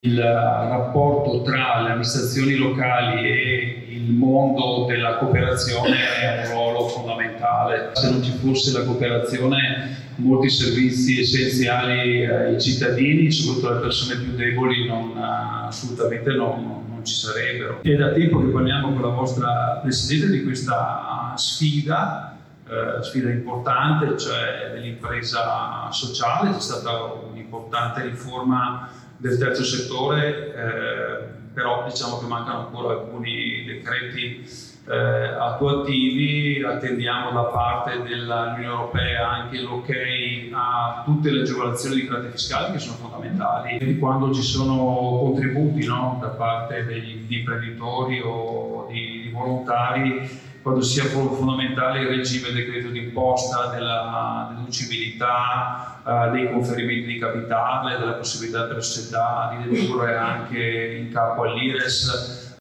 Il confronto a più voci con le testimonianze di cooperatori sociali da Nord a Sud ha posto una serie di questioni sull’inclusione lavorativa e il ruolo delle imprese sociali affermando il valore non solo sociale ma anche economico all’interno del sistema Paese. Su queste richieste ha risposto il sottosegretario al ministero del Made in Italy Massimo Bitonci.